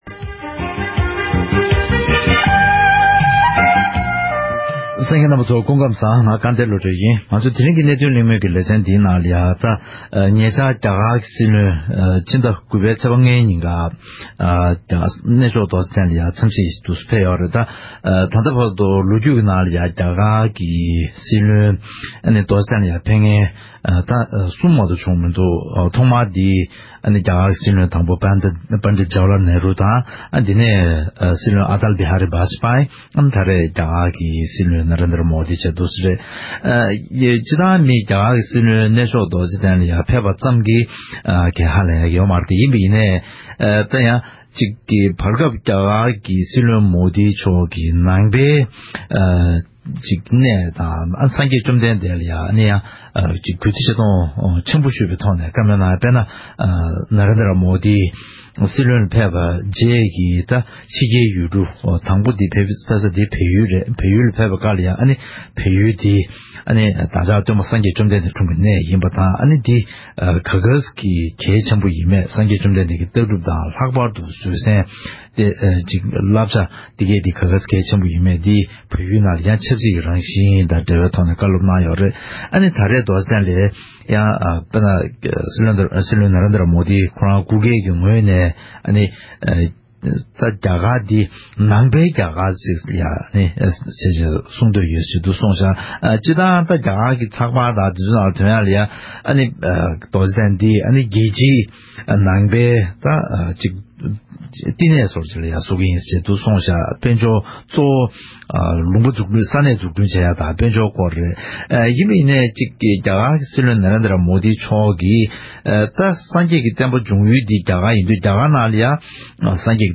དེའི་སྐོར་གླེང་མོལ་ཞུས་པའི་ལས་རིམ་ཞིག་གསན་རོགས་གནང་།